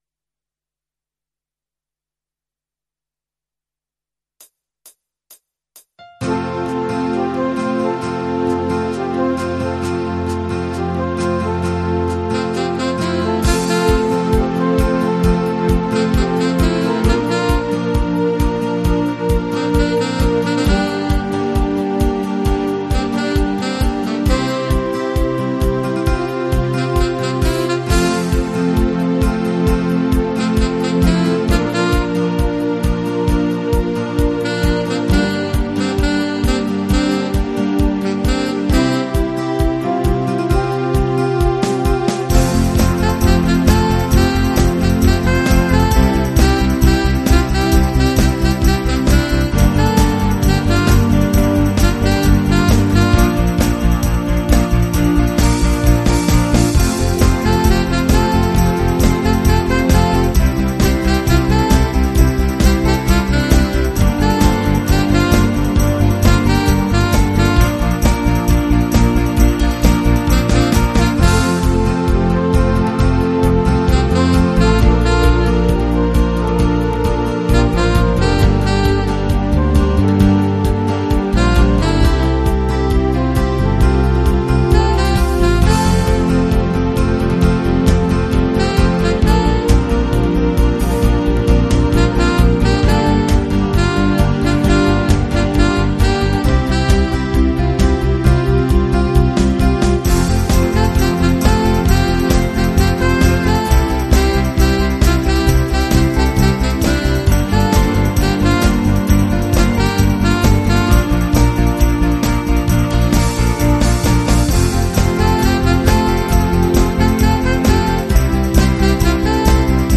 au format MIDI Karaoke pro.